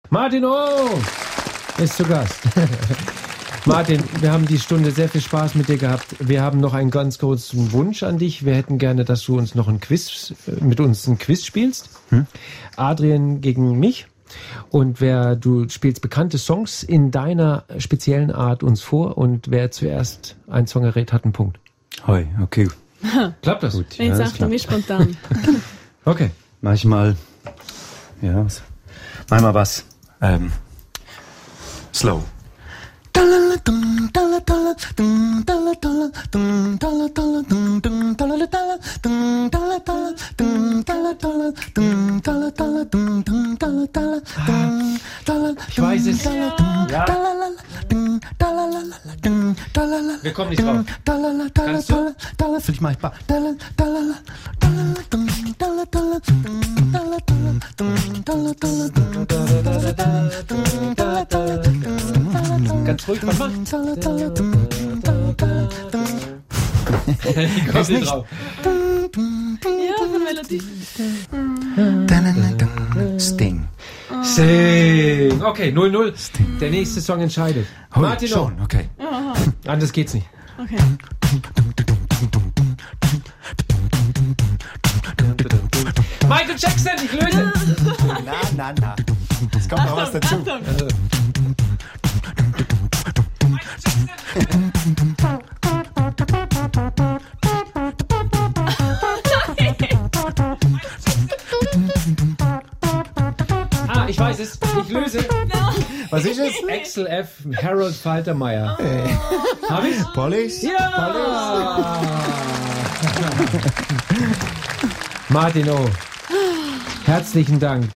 «Dm-dalla-dalla, dm-dalla-dalla» hört sich nach einem Song an
Ein unglaublicher Mann mit einem komischen Instrument war zu Gast in der 67. Ausgabe der Sendung Aussenstelle Schweiz.